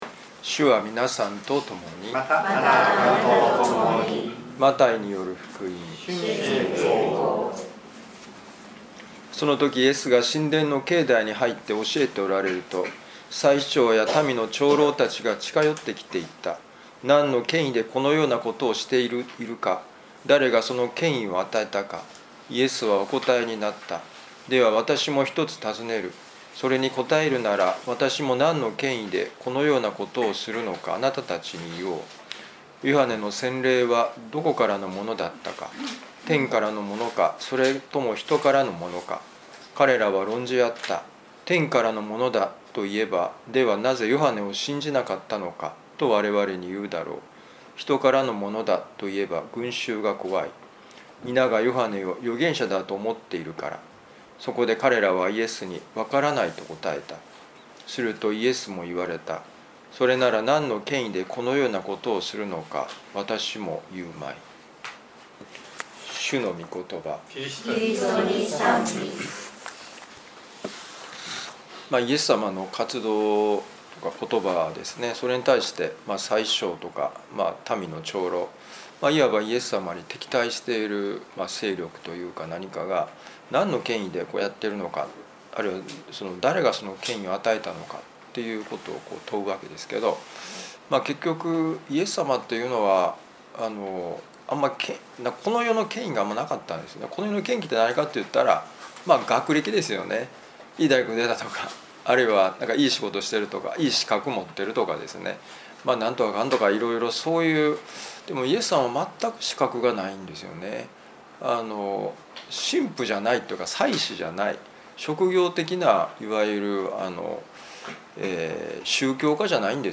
マタイ福音書21章23-27節「イエスの権威とは」2025年12月15日ミサ旅路の里